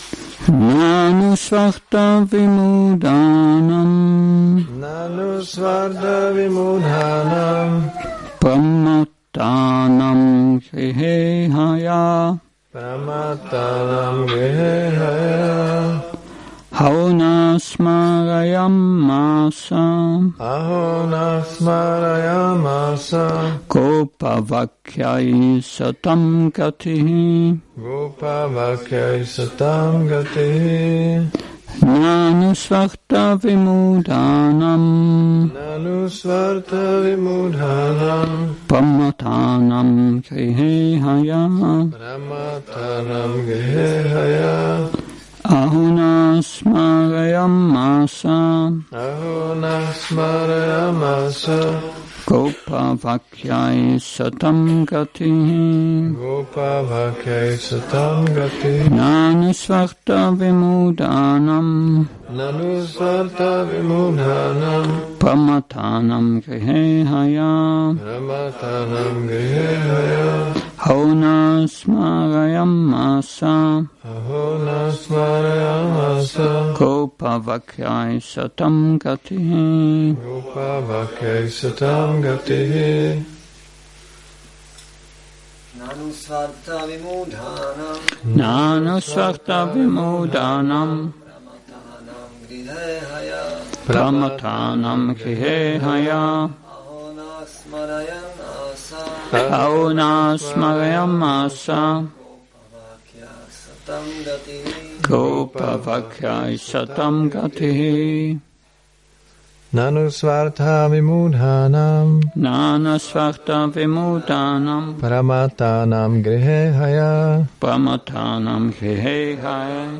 Šrí Šrí Nitái Navadvípačandra mandir
Přednáška SB-10.23.45